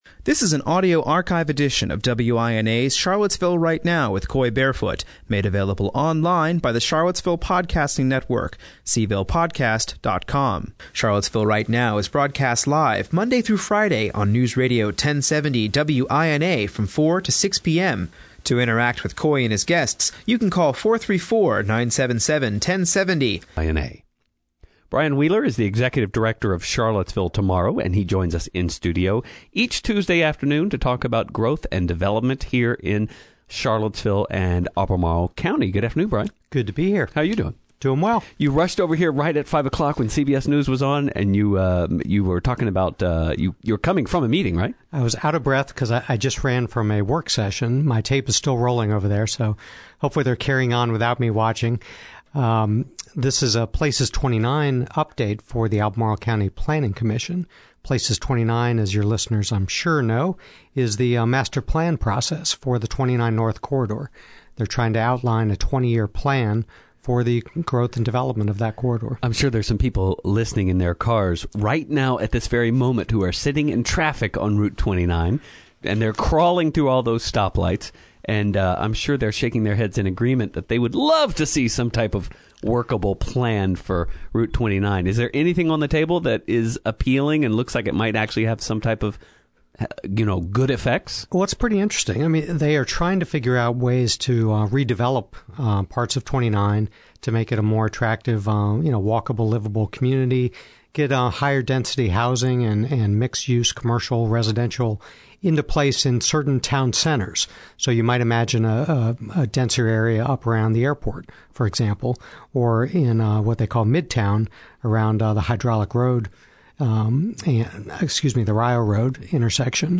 Callers had questions on the number of residential units proposed for the Places29 area and on plans for improving roads around Pantops.